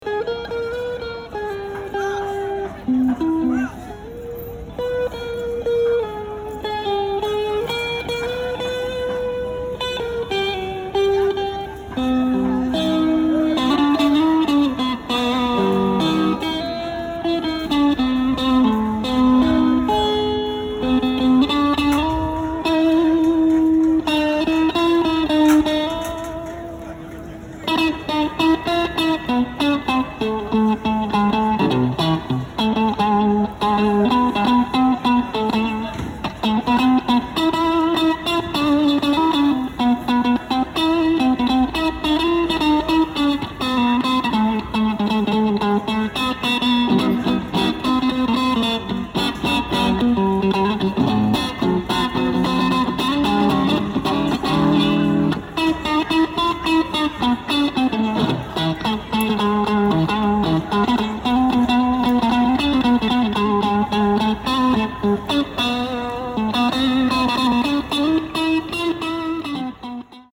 Парень с псом на площади исполняет мелодию на электрогитаре